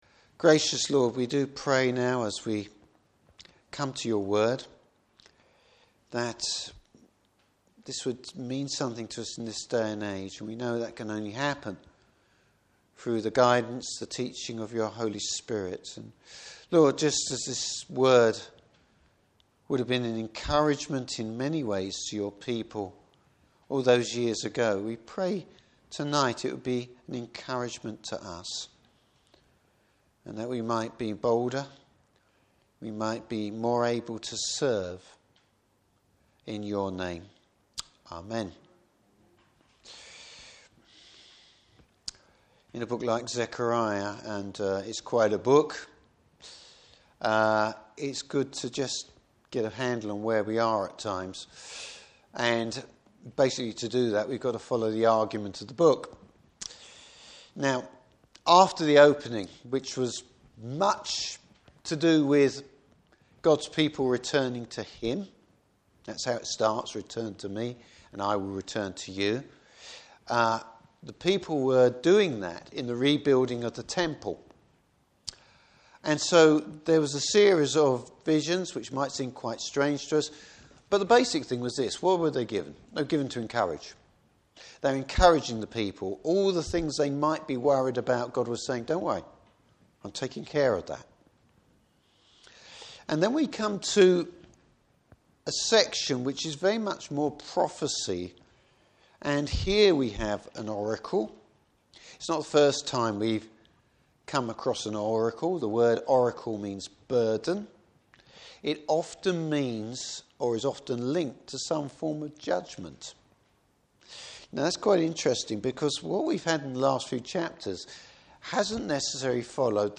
Service Type: Evening Service The Lord fights for his people!